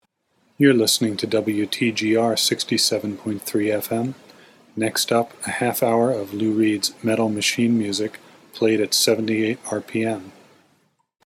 I spoke in the obligatory disinterested monotone
(MP3 recording of a simulation here), played obscure bands on minor labels, and inflicted unlistenable electronic garbage upon my listeners.